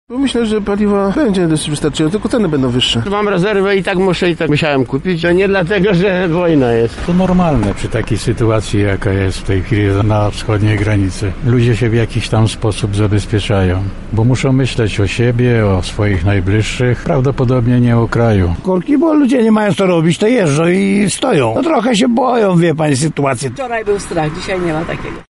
Zapytaliśmy klientów jednej z lubelskich stacji benzynowych, co sądzą na temat pogoni za paliwem:
SONDA